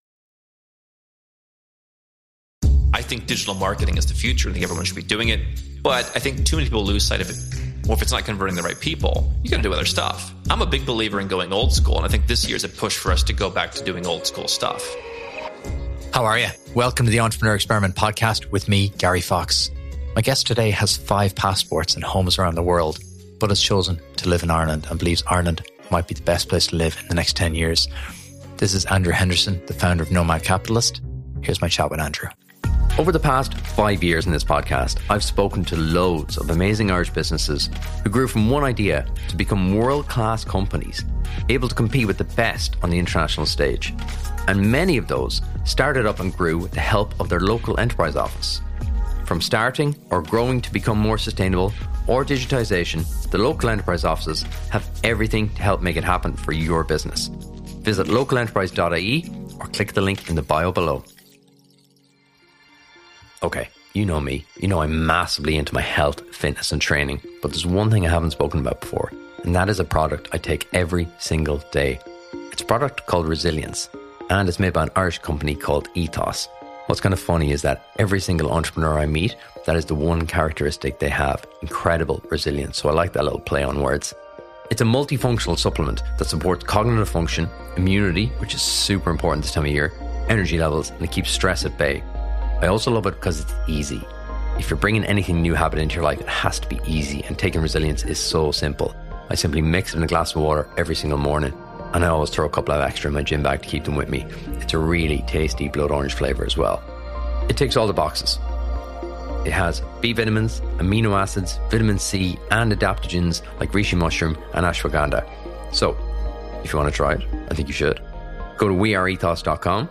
In this revealing chat, we explore the concept of being a global citizen. How can this save you money, cut your tax, improve your quality of life & open up a world of investment choices.